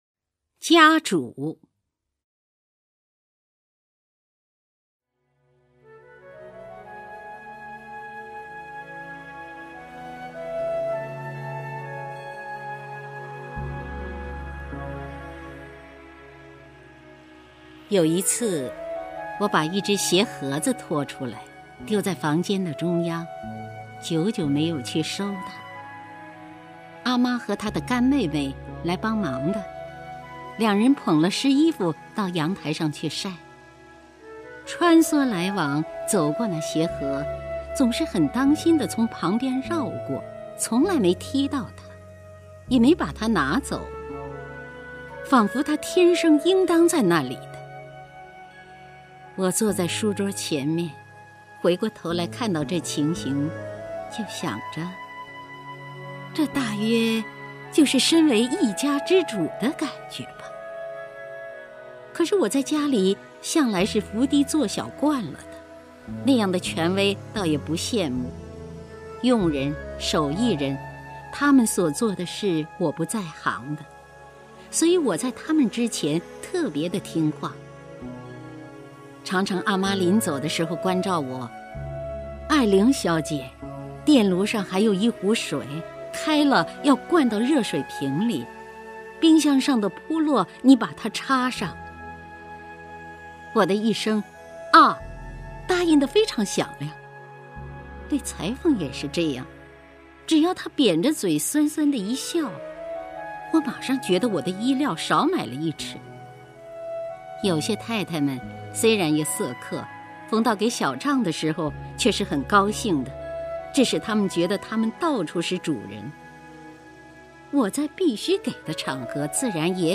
姚锡娟朗诵：《家主》(张爱玲)
名家朗诵欣赏 姚锡娟 目录
JiaZhu_ZhangAiLing(YaoXiJuan).mp3